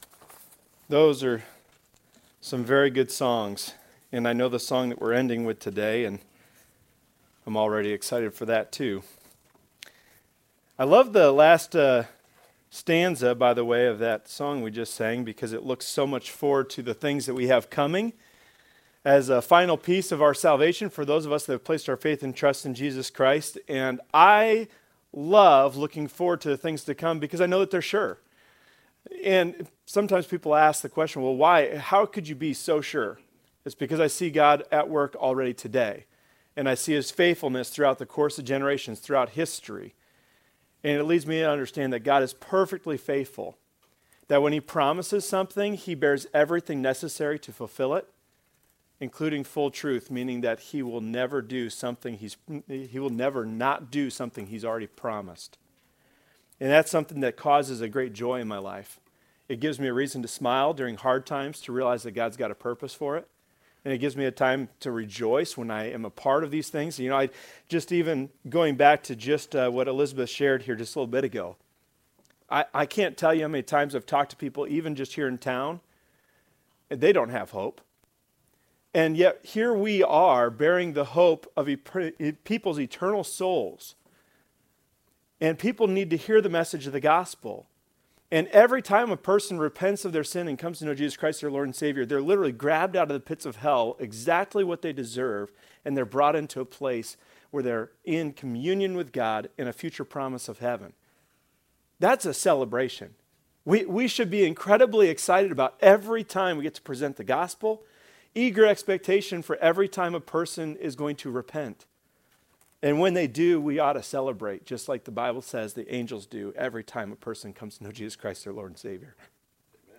We apologize that only the first part of the sermon was recorded…